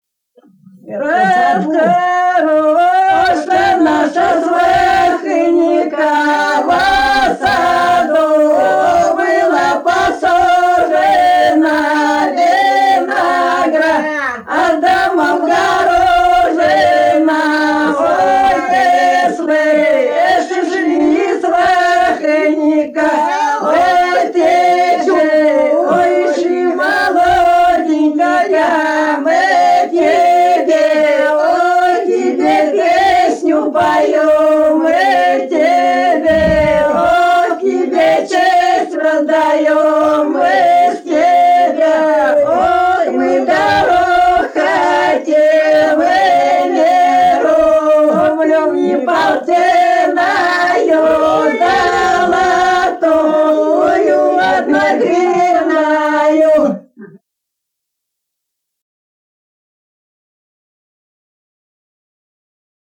Народные песни Касимовского района Рязанской области «Расхороша наша свахыника», свадебная.